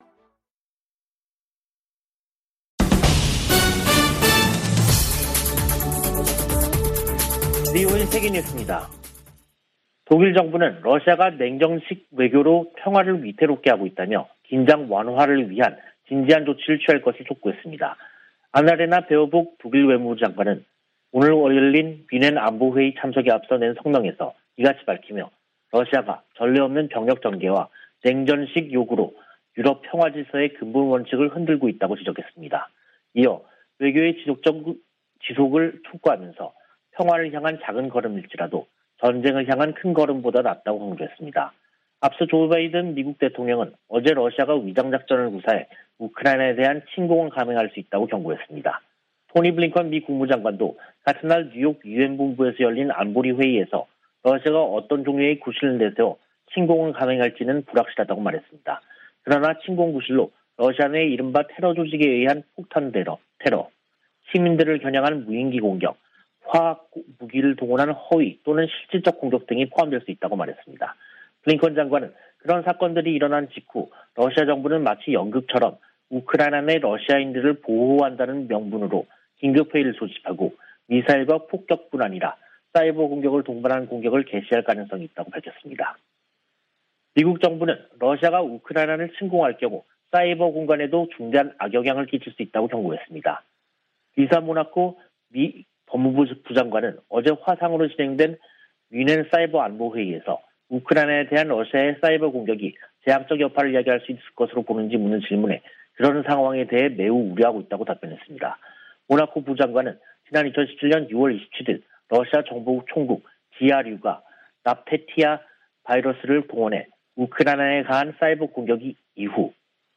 VOA 한국어 간판 뉴스 프로그램 '뉴스 투데이', 2022년 2월 18일 2부 방송입니다. 미 국무부 동아태 차관보가 미국, 한국, 일본의 최우선 과제로 북한 핵·미사일 위협 대응을 꼽았습니다. 해리 해리스 전 주한 미국대사는 대화를 위해 북한 위협 대응 능력을 희생하면 안된다고 강조했습니다. 북한이 가상화폐 자금에 고도화된 세탁 수법을 이용하고 있지만 단속이 불가능한 것은 아니라고 전문가들이 말했습니다.